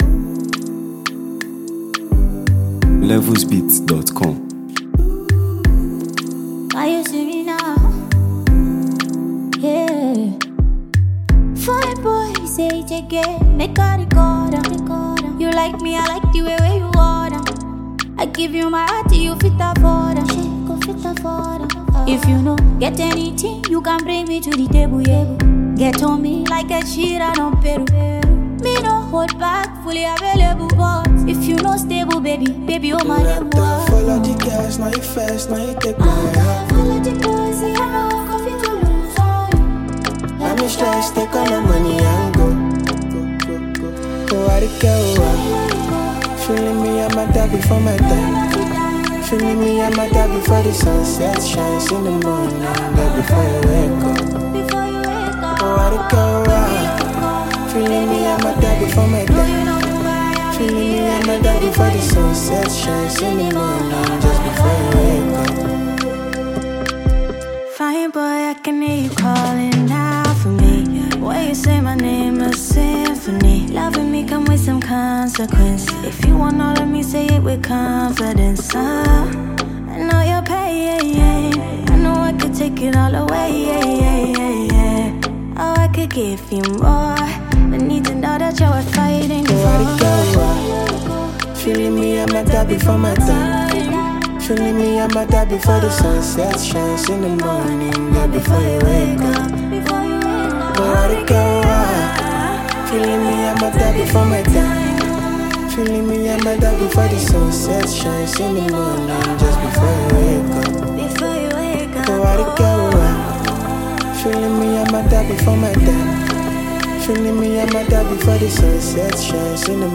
Nigerian singer-songwriter
a versatile female music icon
a renowned Nigerian music prodigy and lyricist.